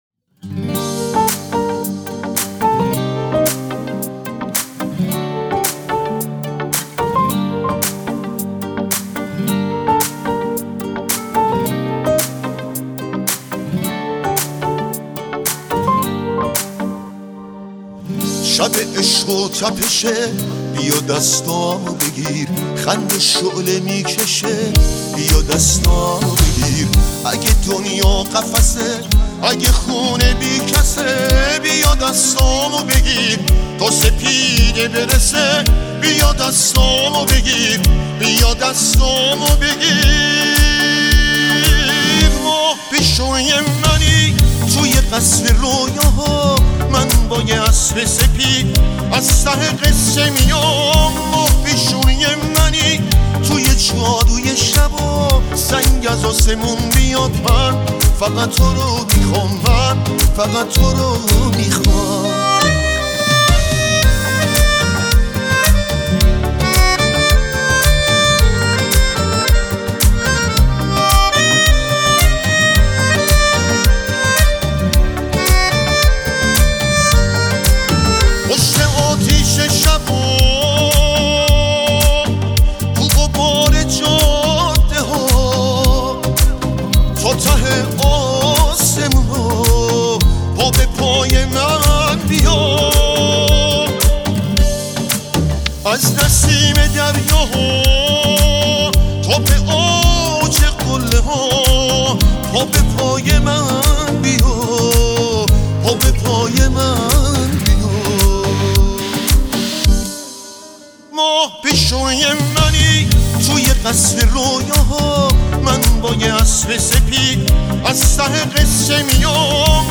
موسیقی